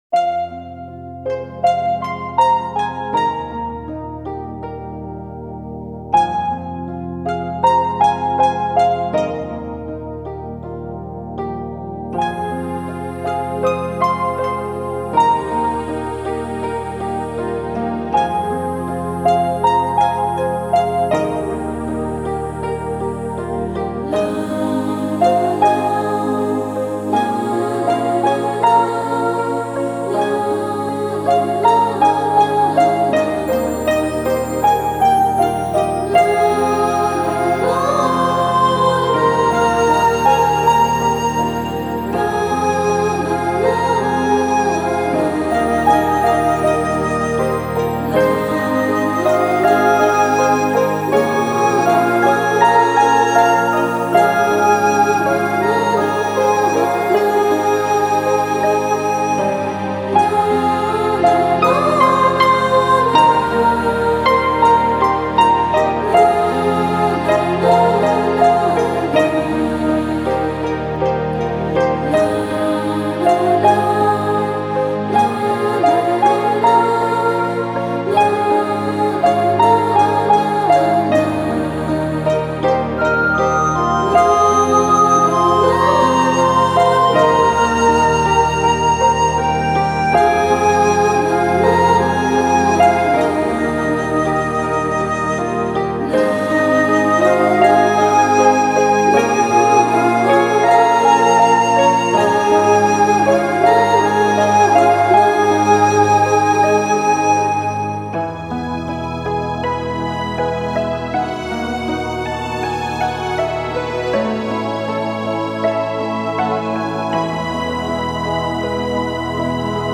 Ps：在线试听为压缩音质节选，体验无损音质请下载完整版 此歌曲为没有填词的纯音乐，请您欣赏